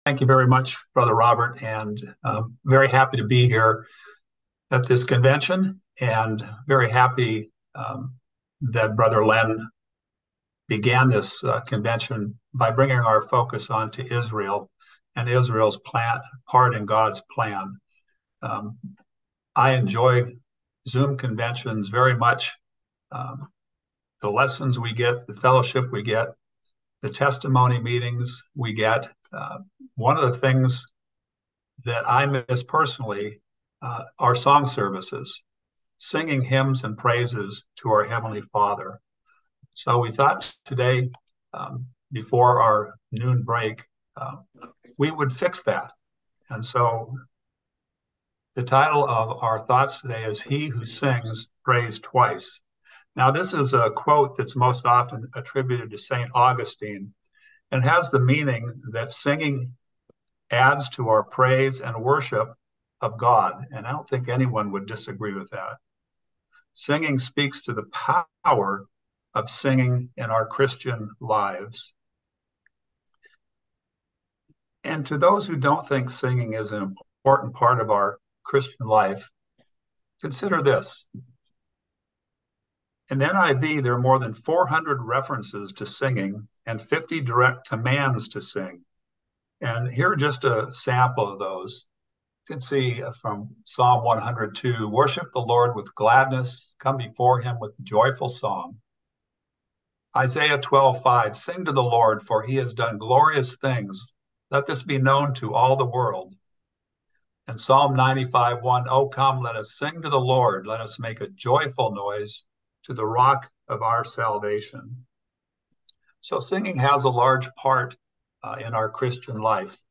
The San Diego Class warmly invites you to the San Diego Convention.